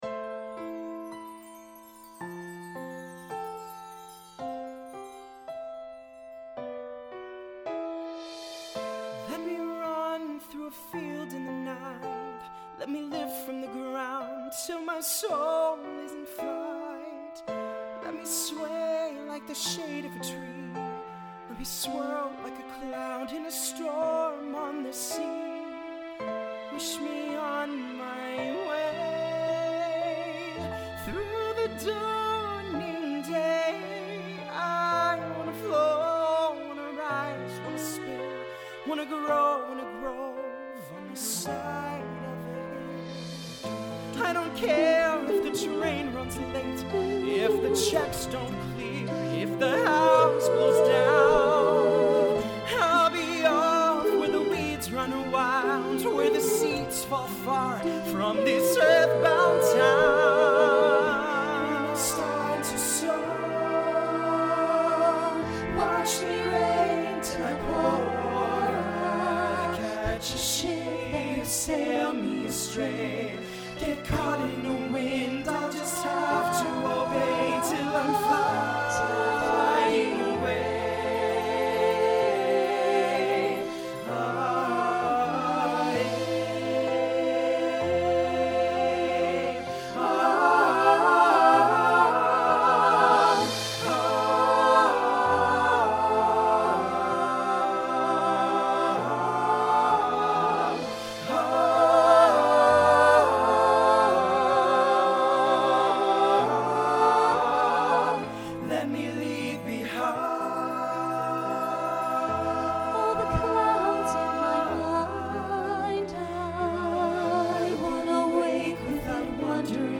Voicing SATB Instrumental combo Genre Broadway/Film
Function Ballad